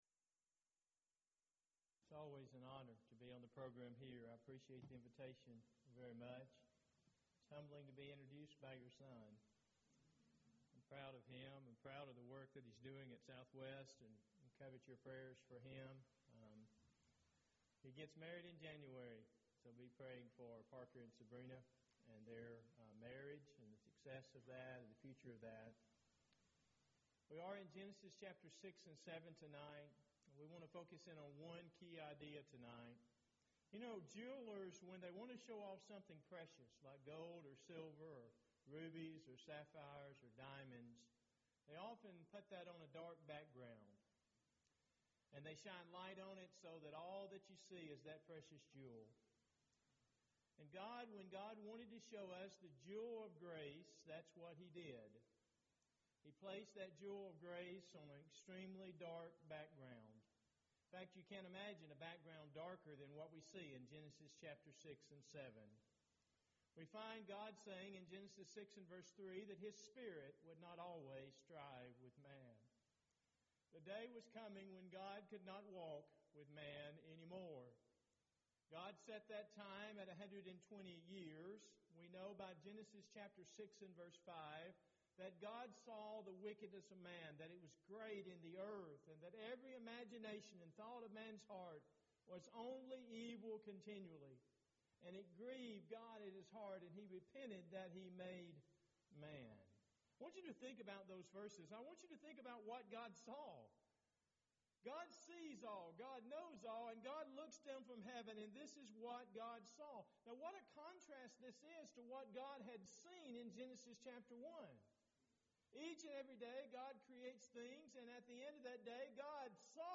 Event: 16th Annual Schertz Lectures Theme/Title: Studies in Genesis